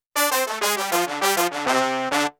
Brass of Power-хэлп
Это звук с проданного корга па икс 3-пользовал его постоянно, скучаю без него :) Киньте ссылку плиз на похожую библу, синтезатор и прочее.